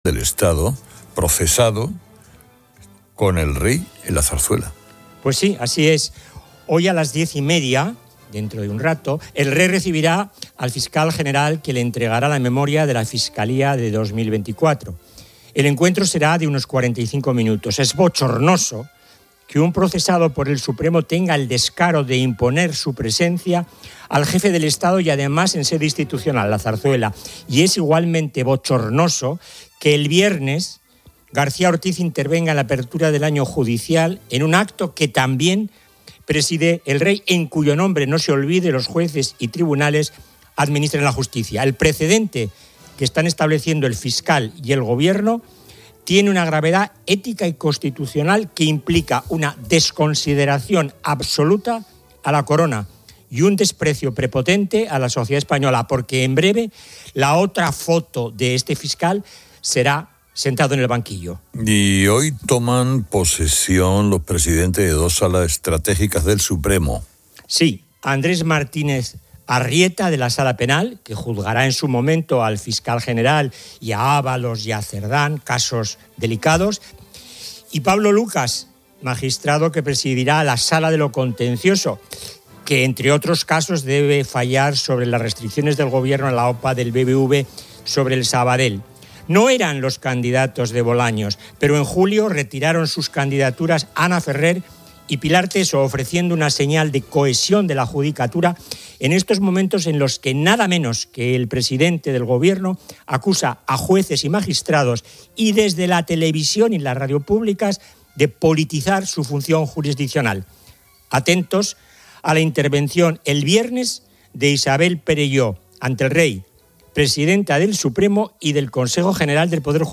La segunda parte se centra en una entrevista con el escritor cubano Leonardo Padura, quien presenta su novela 'Morir en la arena'.